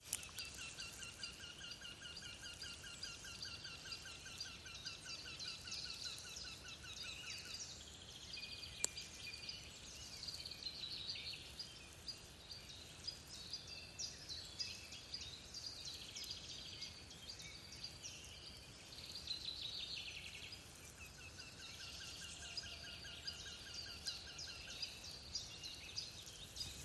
Birds -> Woodpeckers ->
Wryneck, Jynx torquilla
StatusSinging male in breeding season